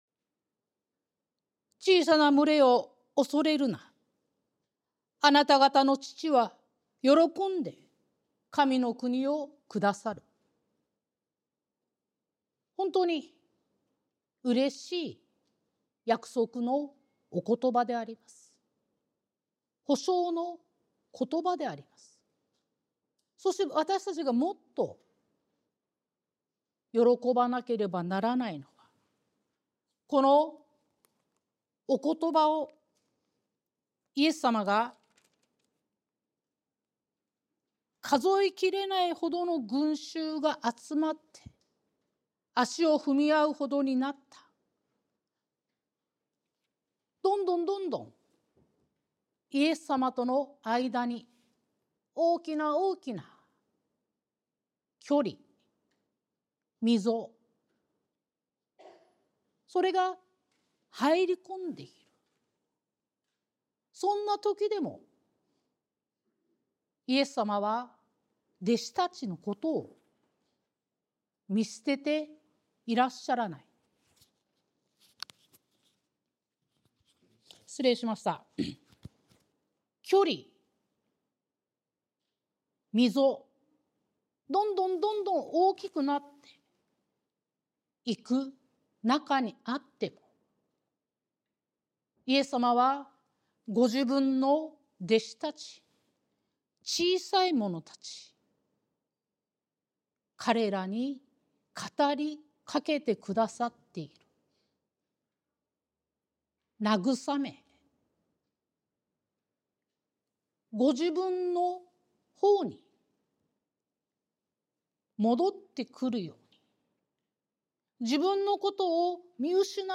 sermon-2025-01-12